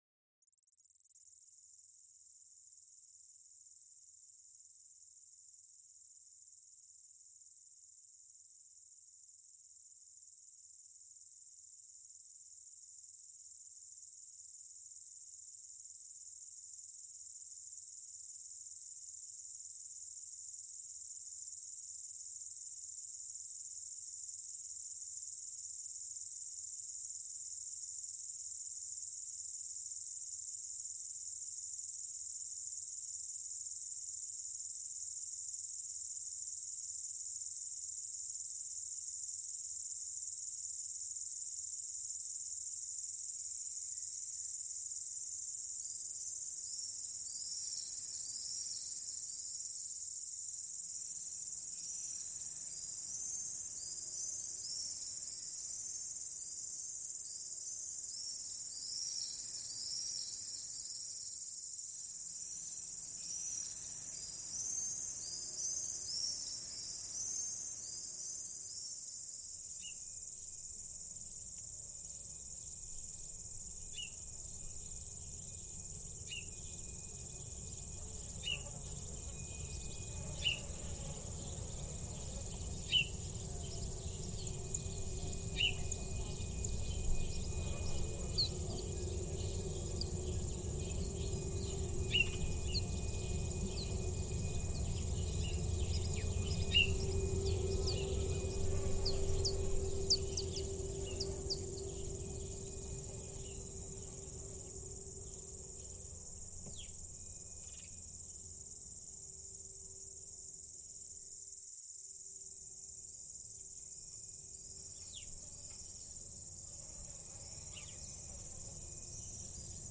It was also important to me to reproduce the calm that manifests itself in the picture.